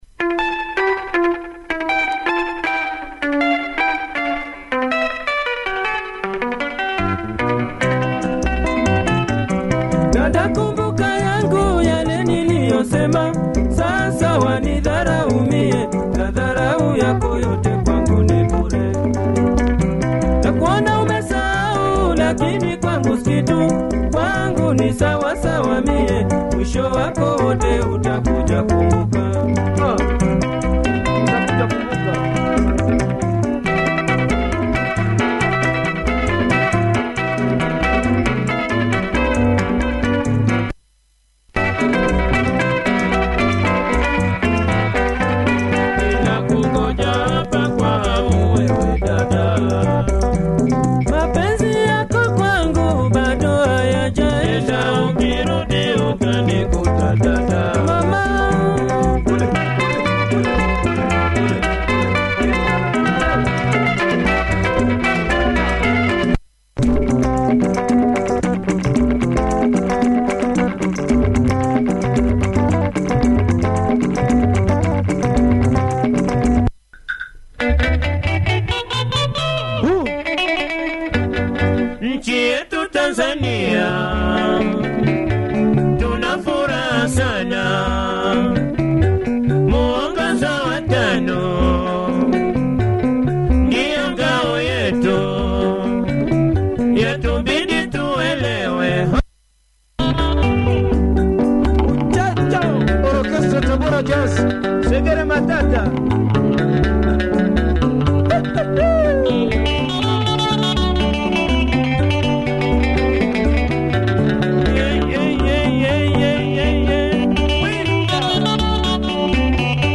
Nice breakdown!